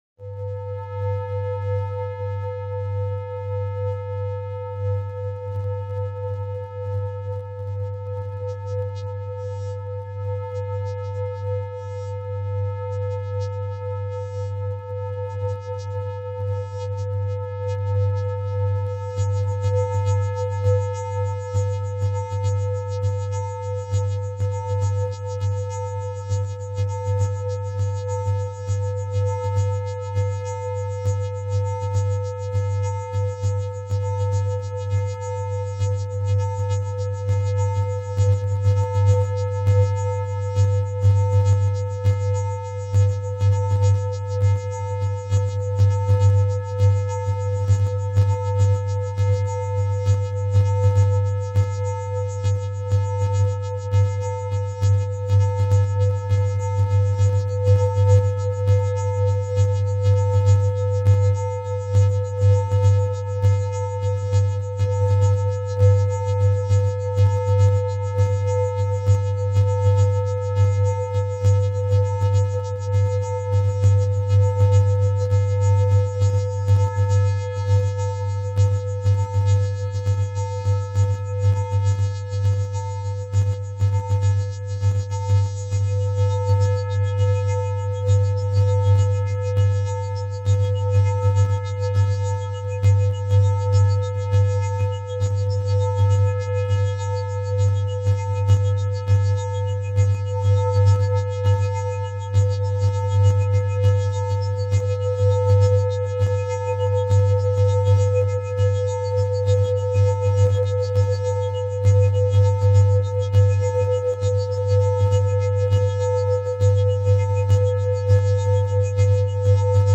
Vocoded Sine